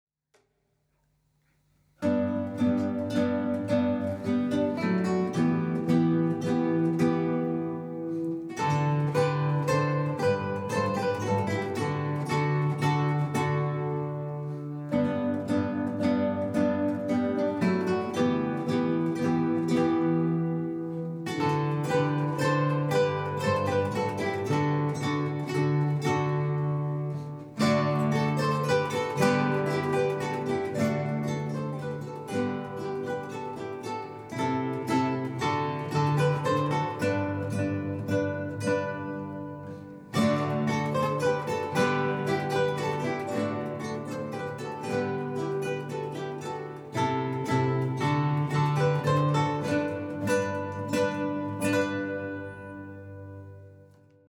Kytarový orchestr
Zvukové nahrávky kytarového orchestru ze dne 22. března 2023